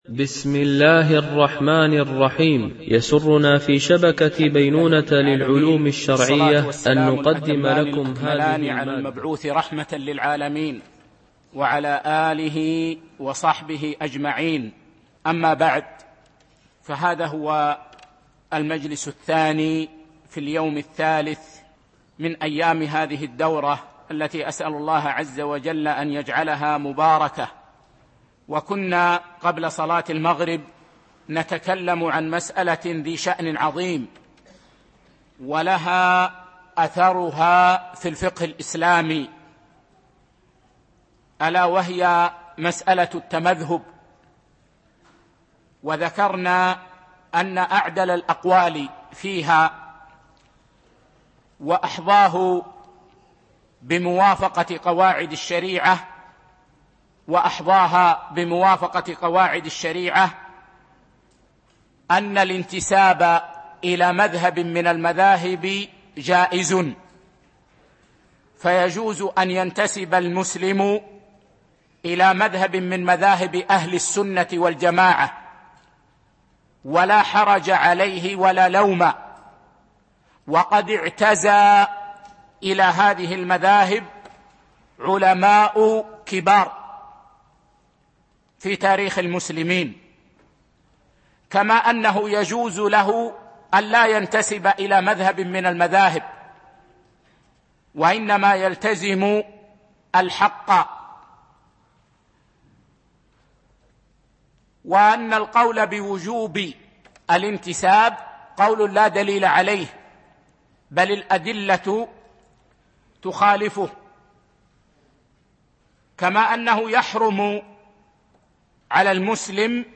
الإعلام بالأئمة الأربعة الأعلام - الدرس 8